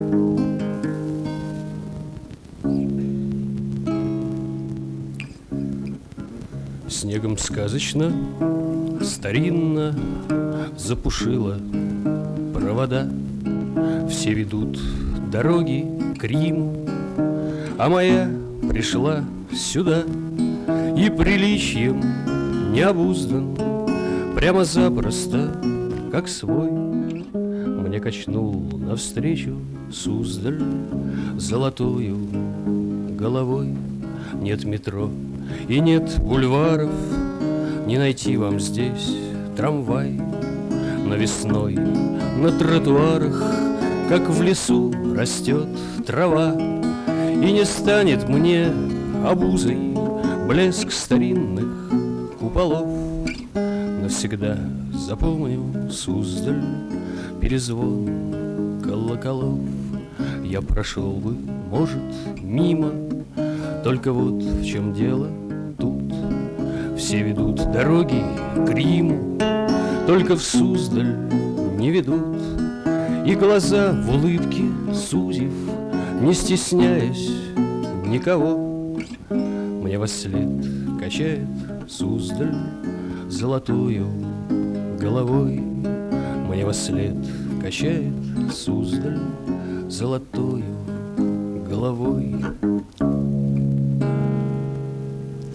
Убрала "разговор"
Такое приятное настроение от этой песни!